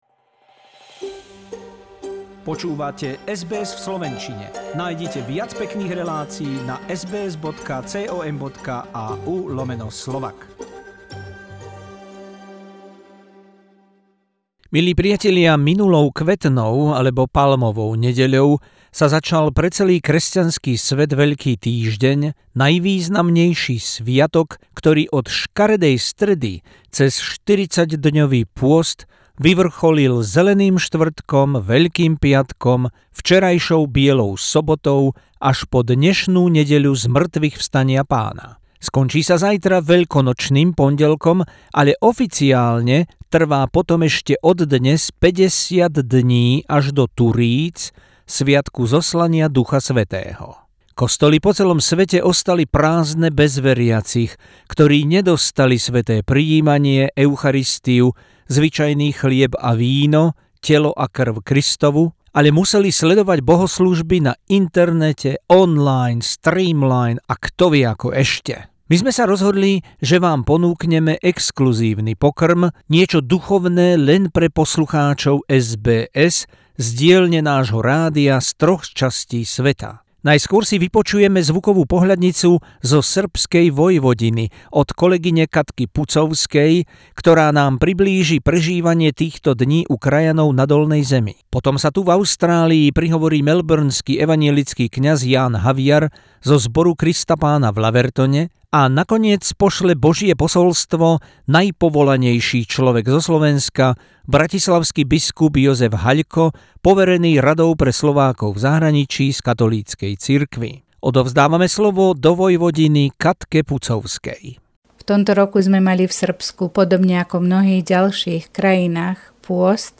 Regular stringer report